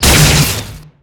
射击.wav